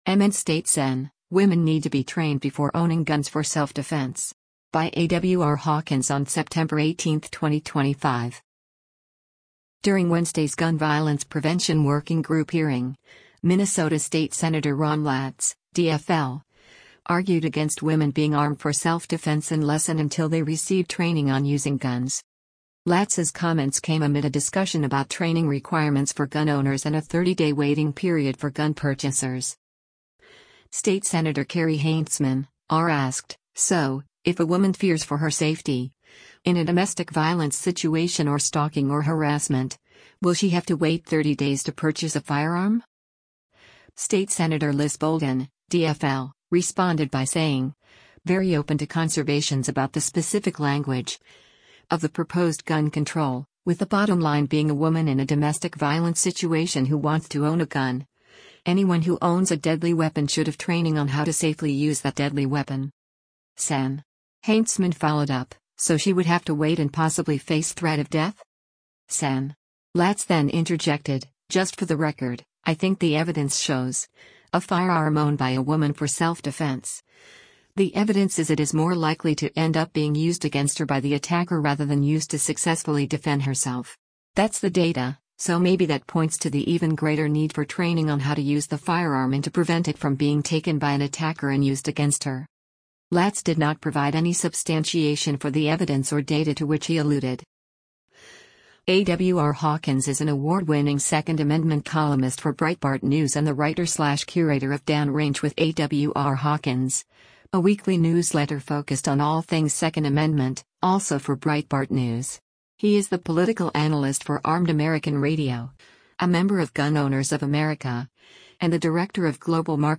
During Wednesday’s Gun Violence Prevention Working Group hearing, Minnesota state Sen. Ron Latz (DFL) argued against women being armed for self-defense unless and until they receive training on using guns.